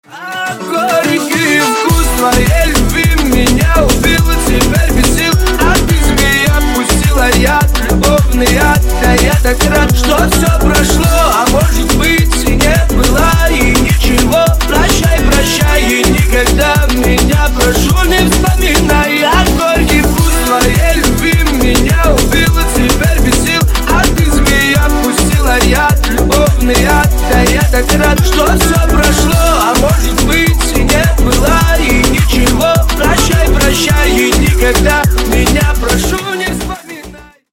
Кавказские Рингтоны
Рингтоны Ремиксы » # Танцевальные Рингтоны